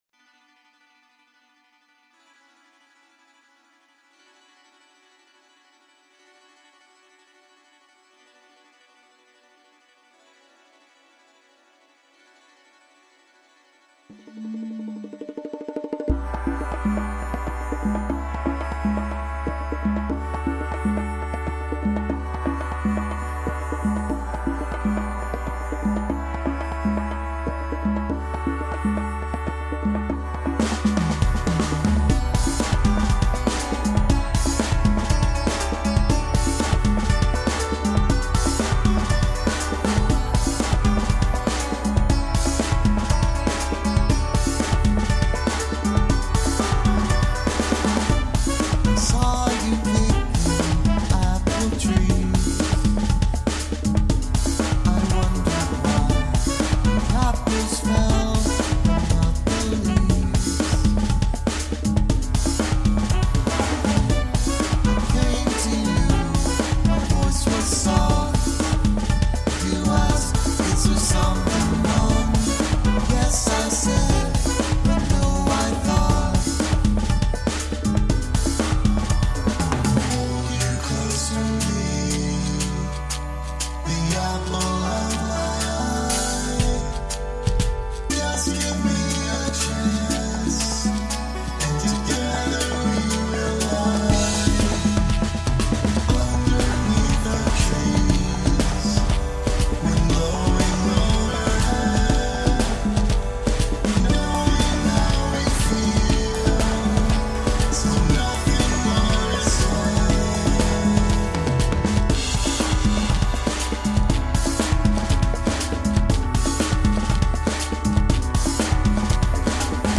Rhythm. Lots of rhythm.
who also provided the sitar, tabla, and tampura you hear.